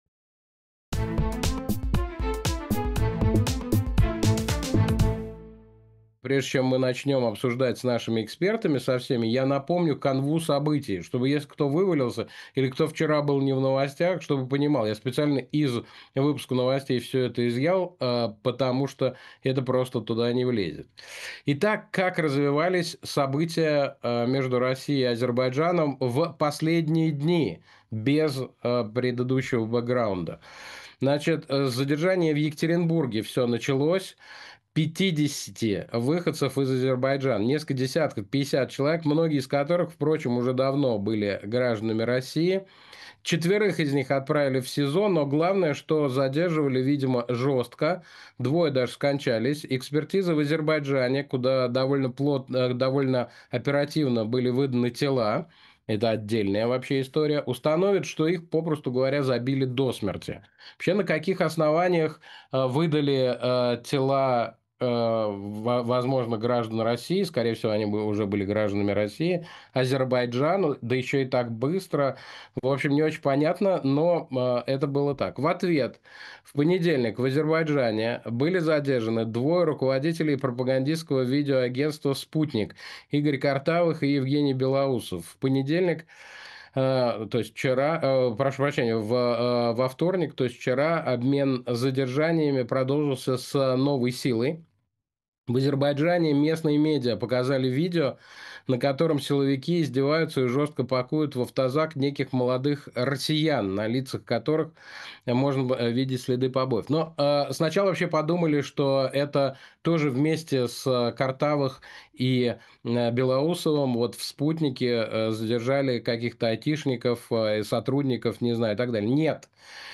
Программу ведет Александр Плющев.